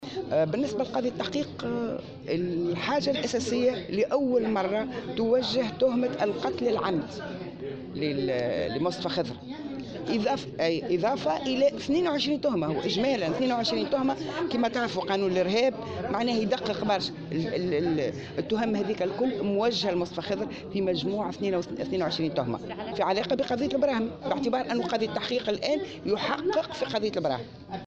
وأضافت على هامش ندوة صحفية عقدتها الهيئة اليوم